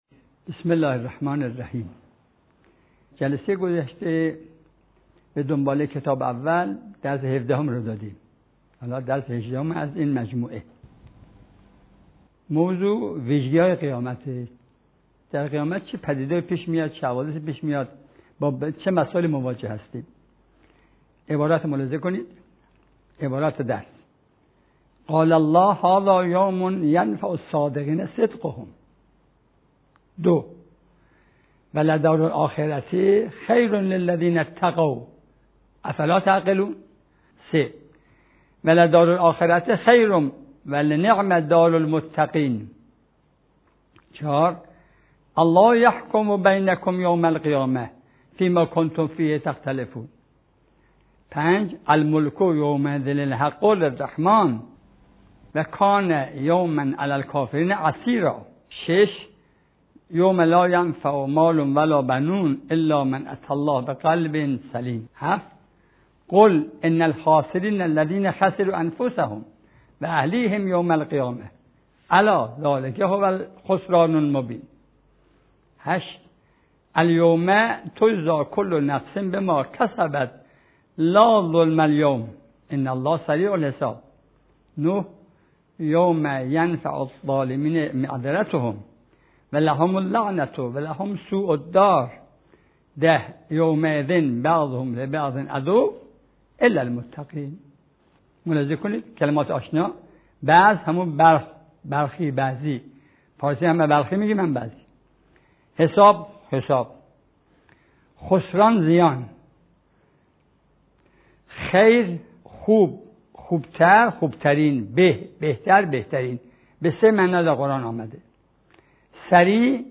آموزش ترجمه و مفاهیم قرآن جلسه هجدهم